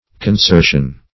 Search Result for " consertion" : The Collaborative International Dictionary of English v.0.48: Consertion \Con*ser"tion\, n. [L. consertio, fr. conserere, -sertum to connect; con- + serere to join.]
consertion.mp3